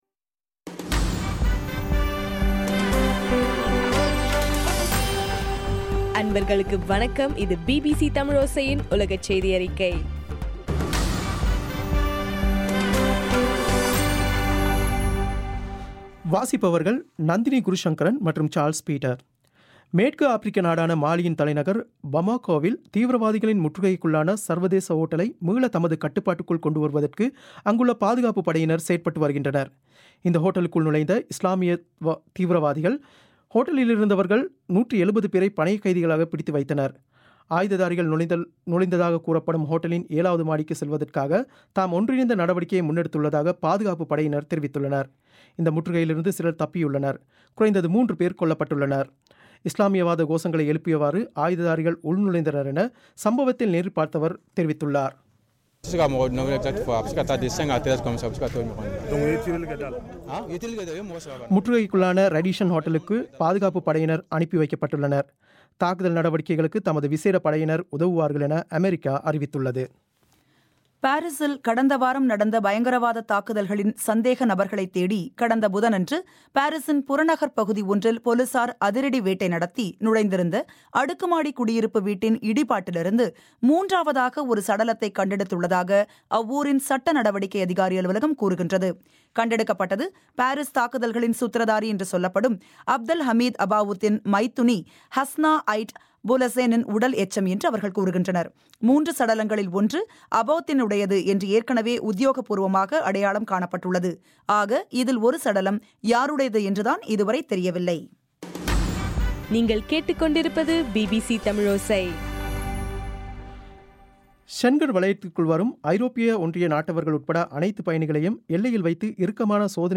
நவம்பர் 20 பிபிசியின் உலகச் செய்திகள்